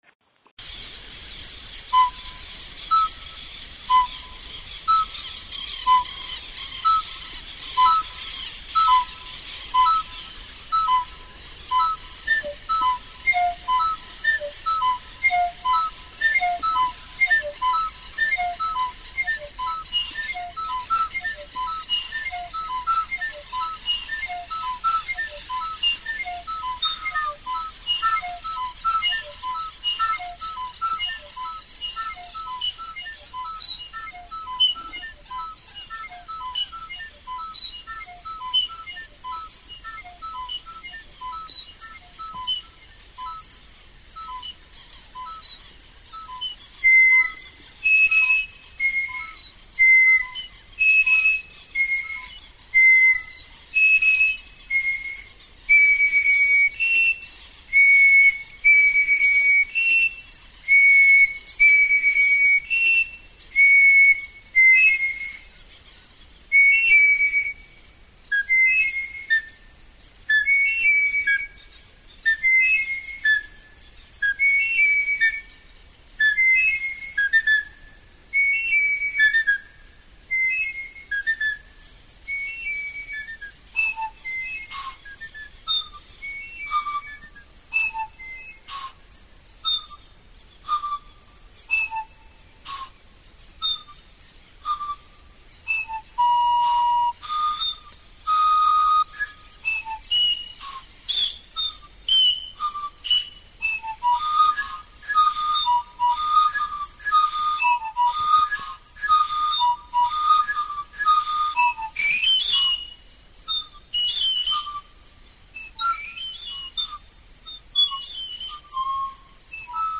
Te invitamos a volver a escuchar el programa de radio We Newenche de este domingo 13 de agosto 2023.